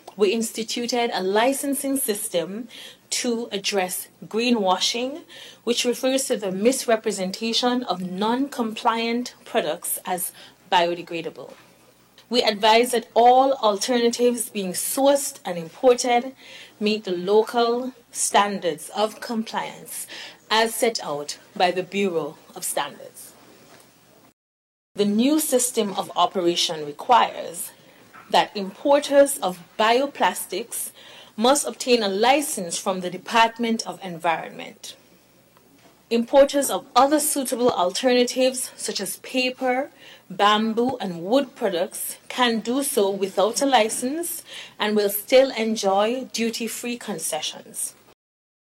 The May 15th, 2025 sitting of the National Assembly featured Federal Minister of Environment, the Hon. Dr. Joyelle Clarke discussing the ongoing phased ban on Single Use Plastics: